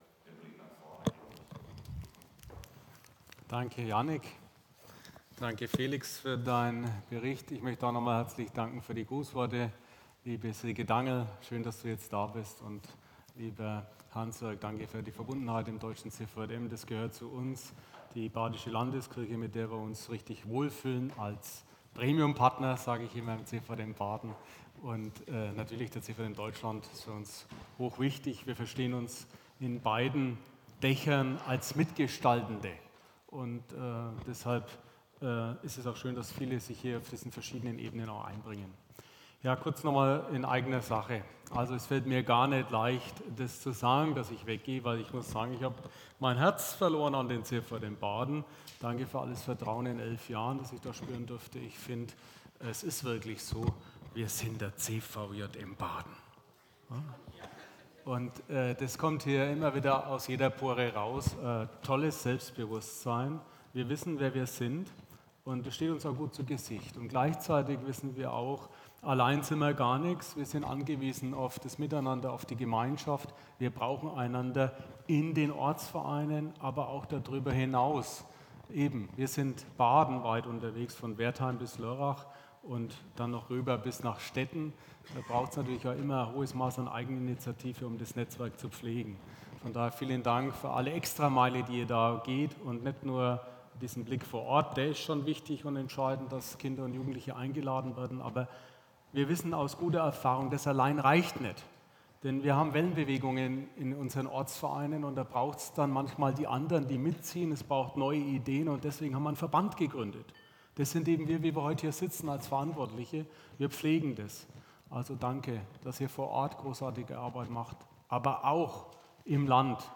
Themenbereich: Vortrag
Format: Delegiertenversammlung
Podcast Beschreibung: Dieses Impulsreferat von der Delegiertenversammlung 2026 des CVJM Badens ermutigt und lädt ein: Gewinne neue Mitglieder, lade Menschen ein, am Verein teilzunehmen und gemeinsam etwas zu bewegen!